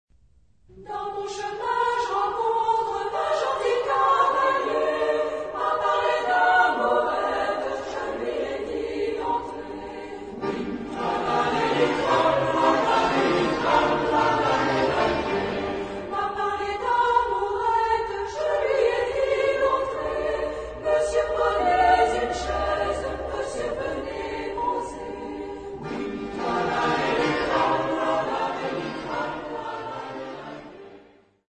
Genre-Style-Forme : Profane ; Populaire ; Chanson à répétition
Caractère de la pièce : modéré
Type de choeur : SSMA  (4 voix égales de femmes )
Tonalité : sol mineur
Origine : Acadie ; Canada